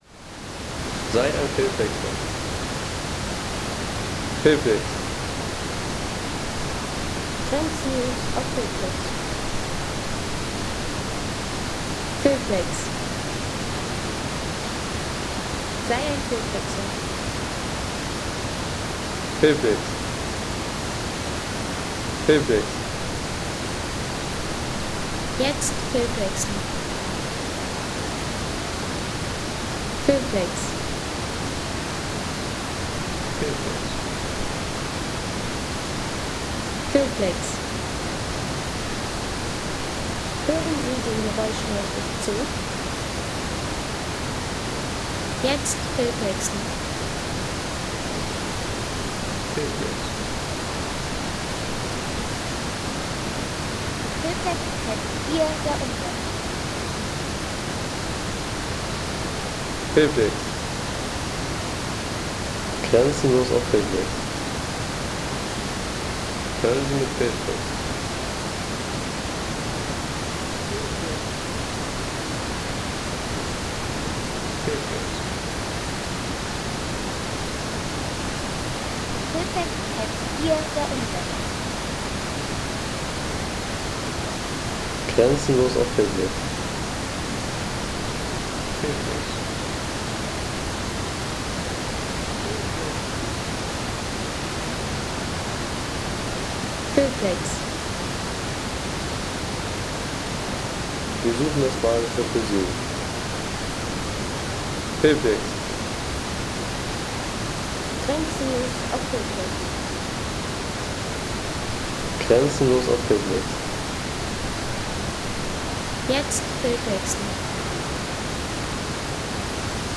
Lehner Wasserfall Home Sounds Landschaft Wasserfälle Lehner Wasserfall Seien Sie der Erste, der dieses Produkt bewertet Artikelnummer: 230 Kategorien: Landschaft - Wasserfälle Lehner Wasserfall Lade Sound....